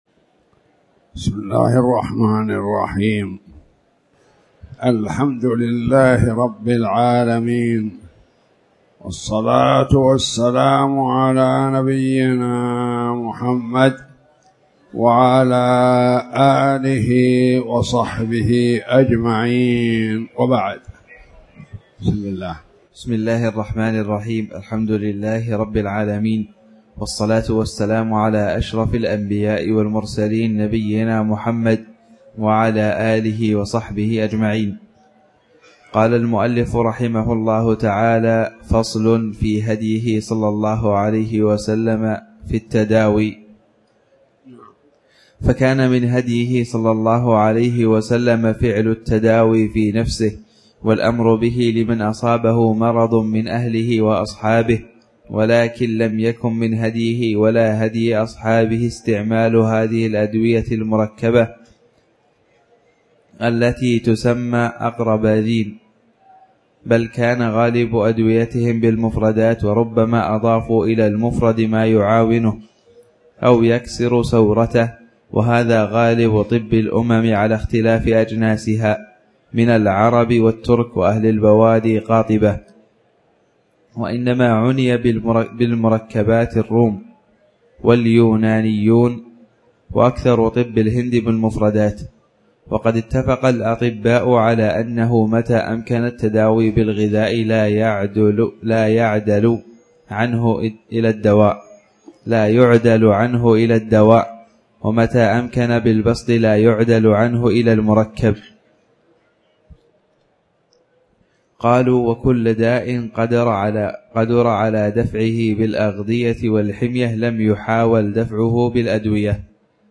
تاريخ النشر ٢٤ ذو الحجة ١٤٣٨ هـ المكان: المسجد الحرام الشيخ